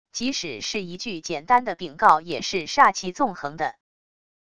即使是一句简单的禀告也是煞气纵横的wav音频